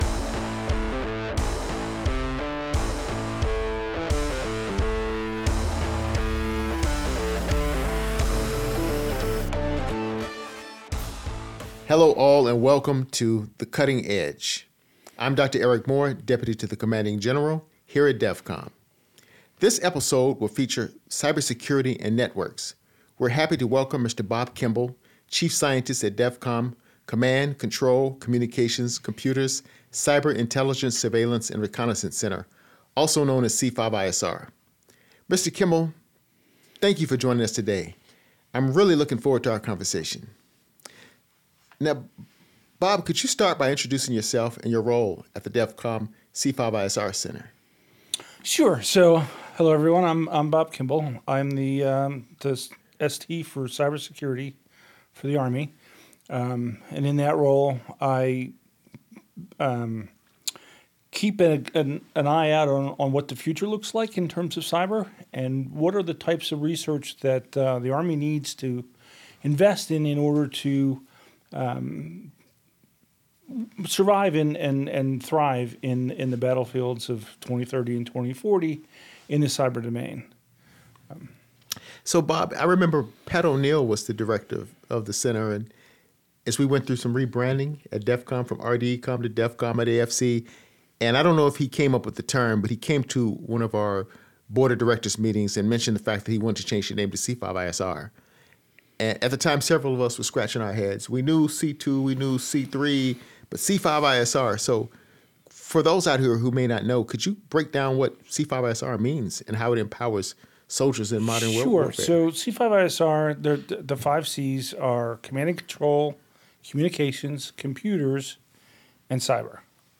this podcast series is dedicated to interviews with leaders from all levels across DEVCOM, its centers, and its research laboratory.